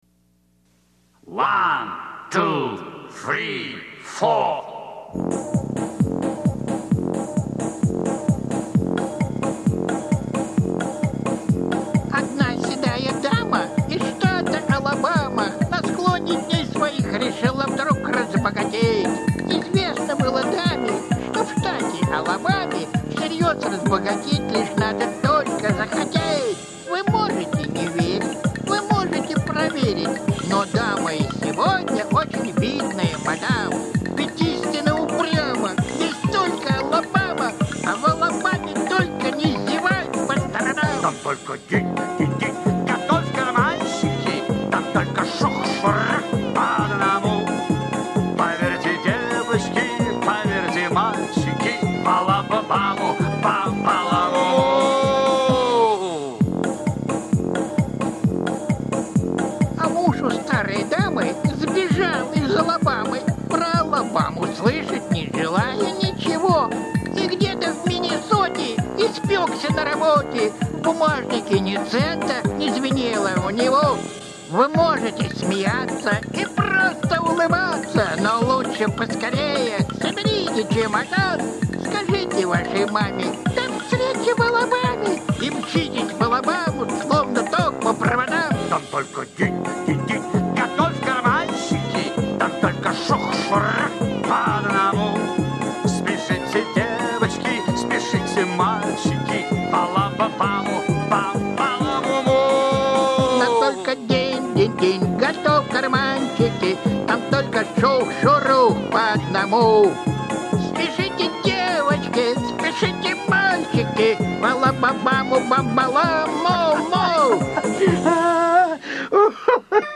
Вождь краснокожих - аудио рассказ О. Генри История о том, как двое мошенников похитили девятилетнего мальчика, сына местного богача.